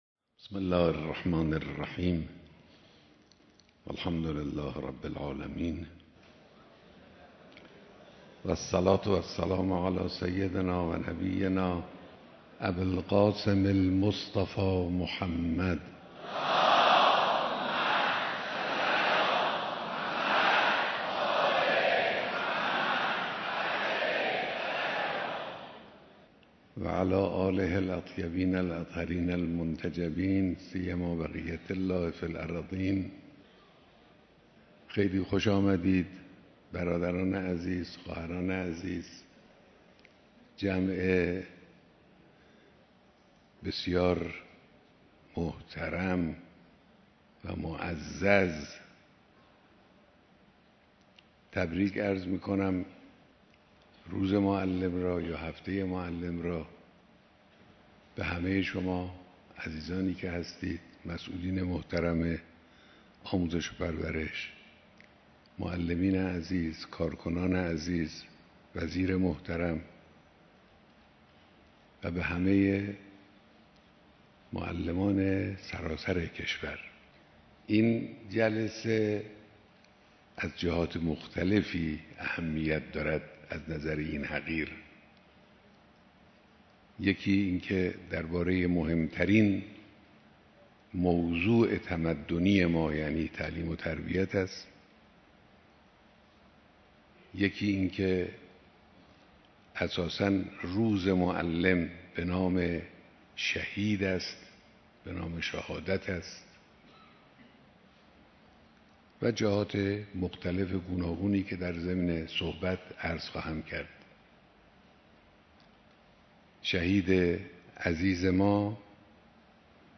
بیانات در دیدار هزاران نفر از معلمان و فرهنگیان سراسر کشور